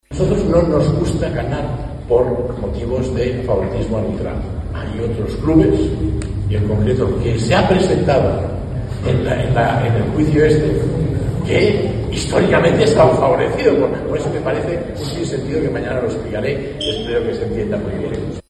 El presidente del Barcelona ha lanzado un dardo al Real Madrid en un acto con los socios antes del Getafe-Barcelona.